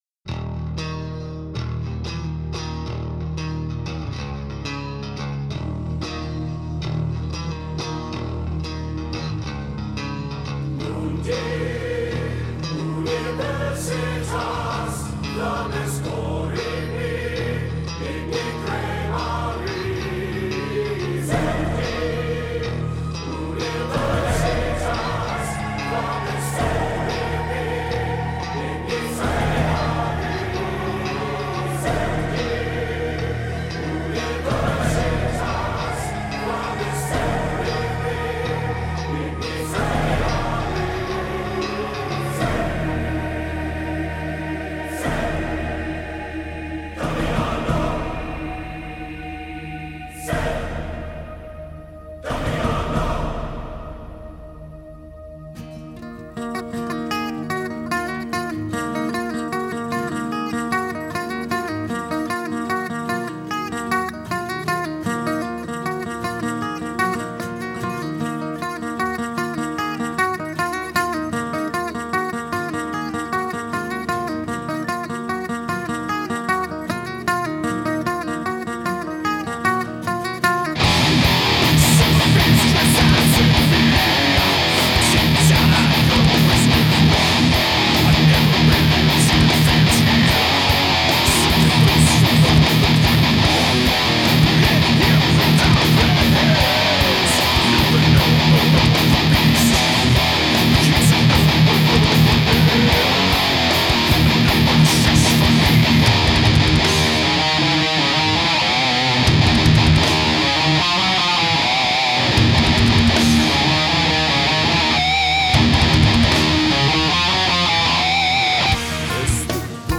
Musician